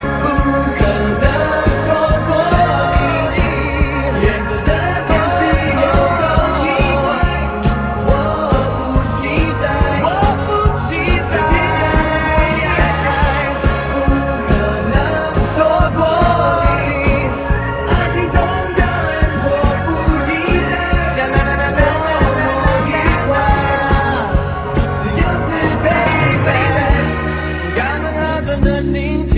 ，再即興口白齊聲輪唱法。